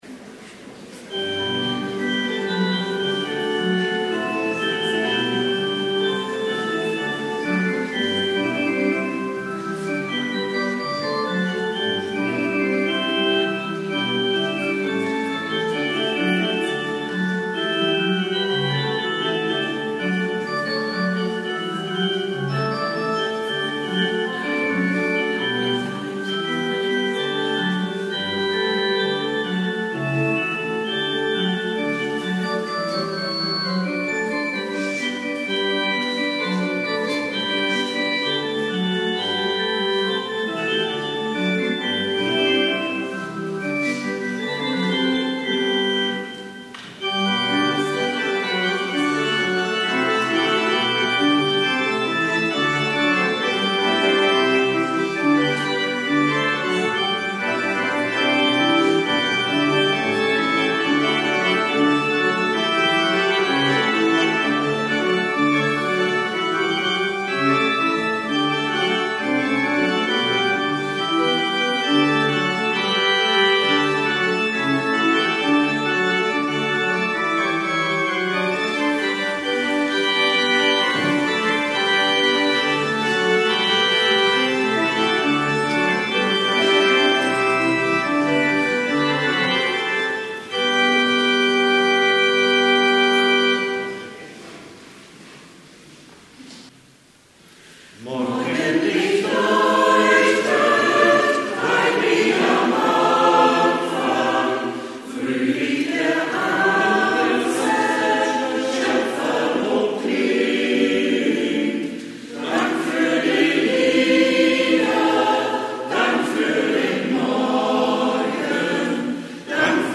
Gottesdienst vom 30.03.2025
Musikalisch gestaltet vom Kirchenchor